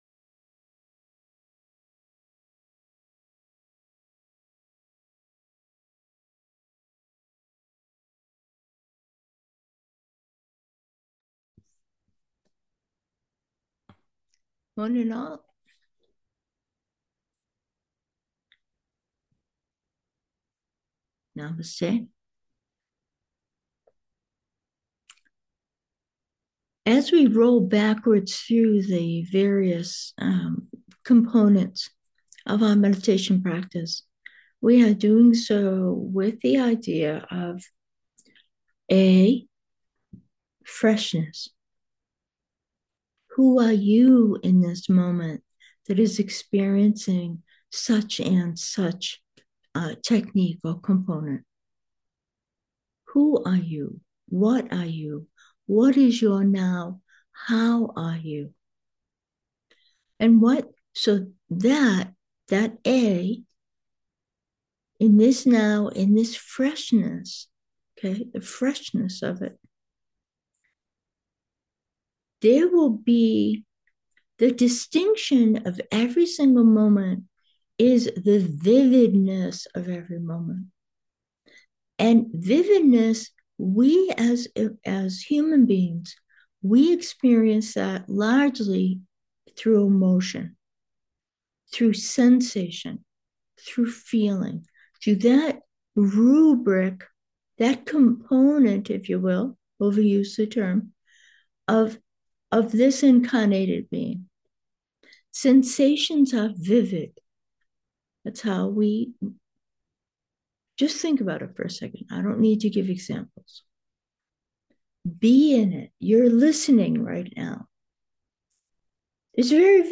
Meditation: sense and sense-ability 1